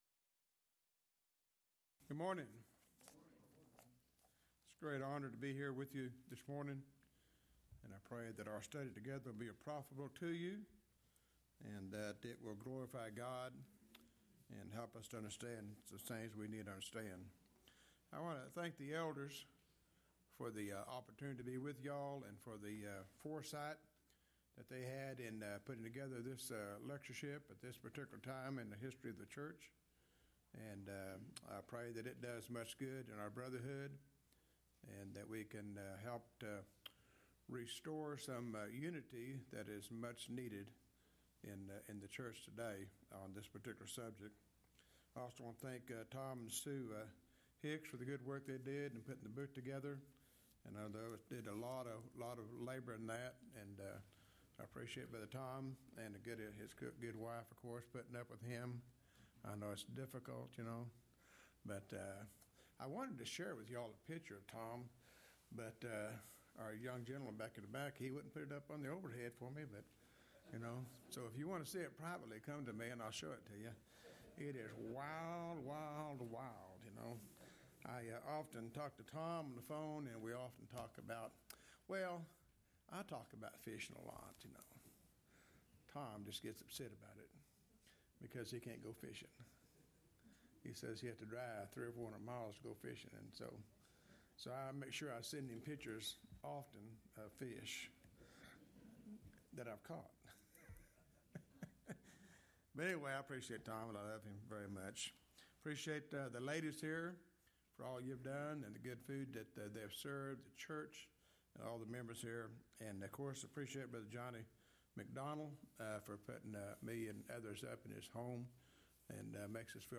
Alternate File Link File Details: Series: Lubbock Lectures Event: 23rd Annual Lubbock Lectures Theme/Title: A New Heaven and a New Earth: Will Heaven Be On A "New Renovated" Earth?
If you would like to order audio or video copies of this lecture, please contact our office and reference asset: 2021Lubbock22 Report Problems